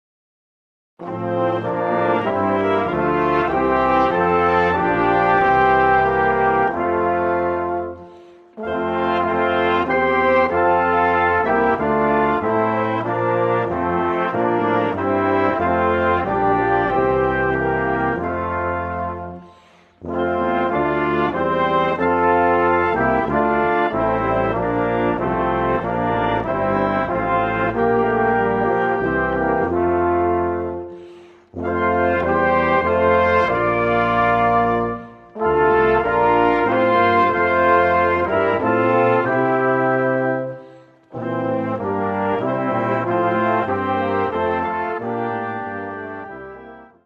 Gattung: Vom Quartett zum Orchester
Besetzung: Ensemble gemischt